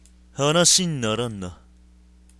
RPG戦闘終了後キャラクター台詞です。
作った当時に出していた声を忘れたので、2通り録ってみました。
しっかし、マイクの集音力高すぎです。
マウスのクリック音まで入ってますよ。